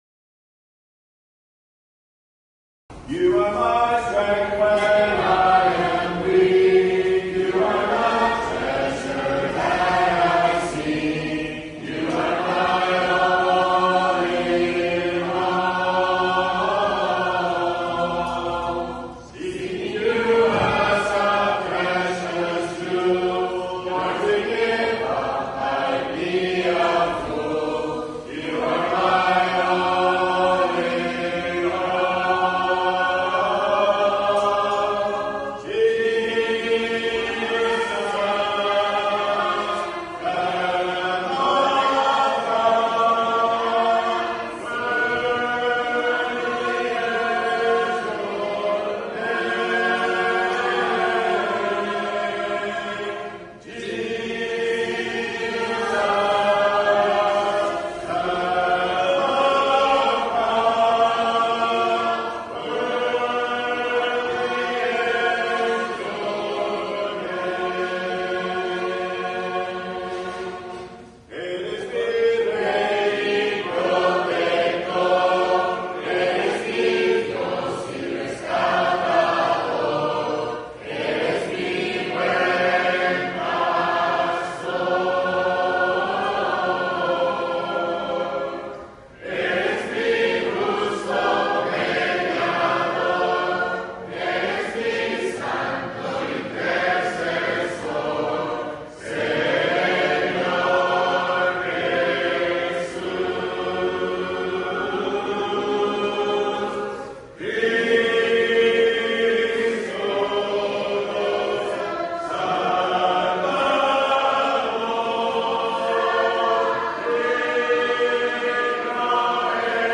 Hymn singing from the Seed Sowers outreach in Mexico in January 2026, some in English, some in Spanish, and some a mix of both. (Recorded in Mexico, Jan 2026)